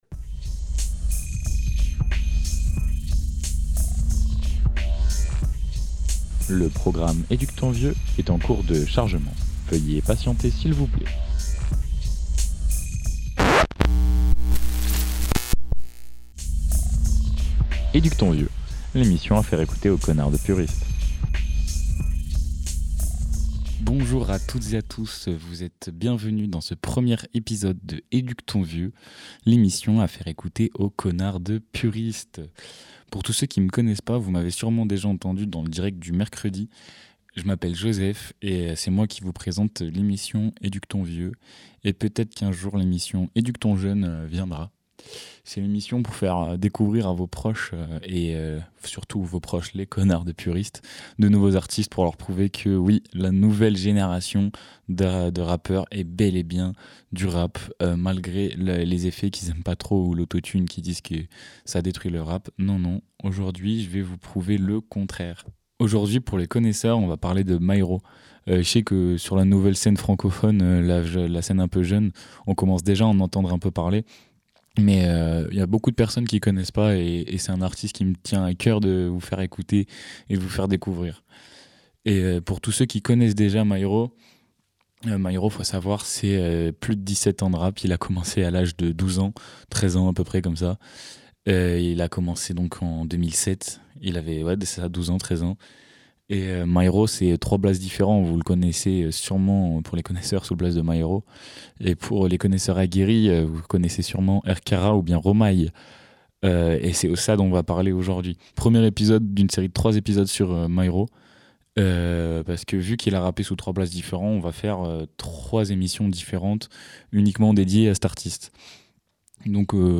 Télécharger en MP3 Bienvenue dans Éduque ton vieux , l’émission qui fait découvrir aux puristes du rap de nouveaux artistes, qui explorent des sonorités différentes de la boom bap à l’ancienne.